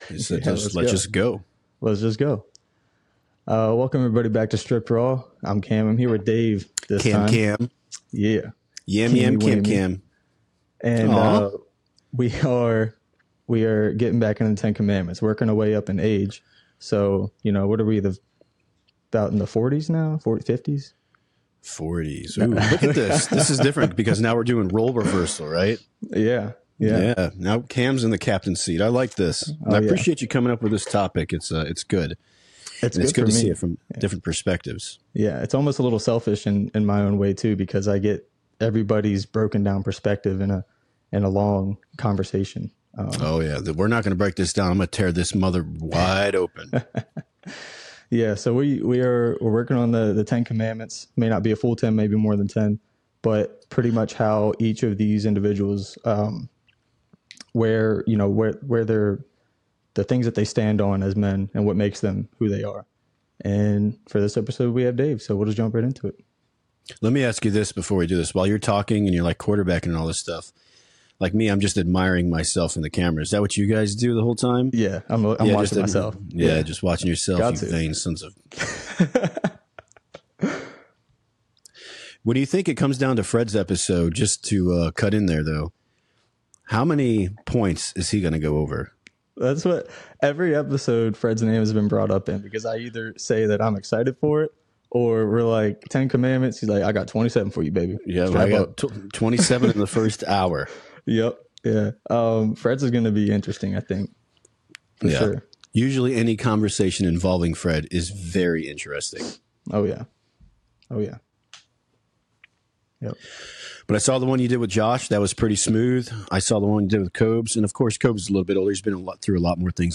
In this conversation, the hosts delve into the Ten Commandments of manhood, exploring themes of self-worth, community, ego, emotional maturity, and the importance of being present while preparing for the future.